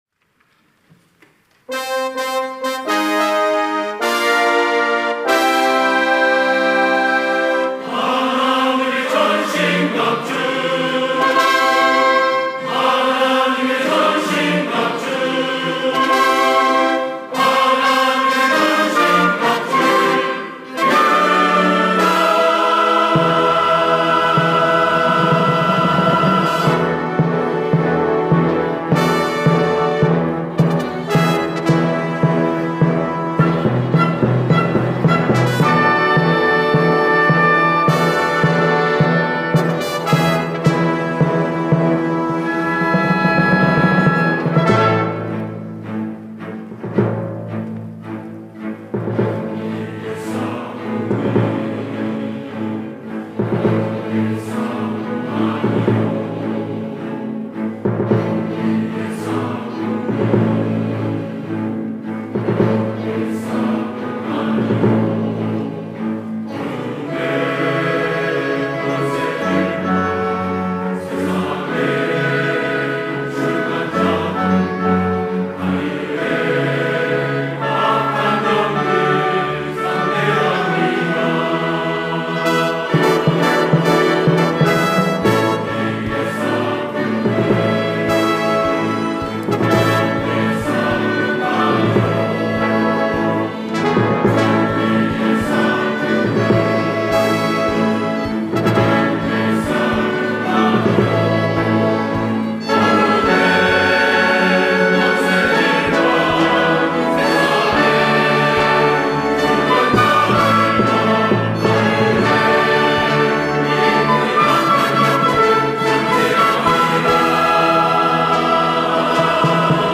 특송과 특주 - 하나님의 전신갑주
연합 찬양대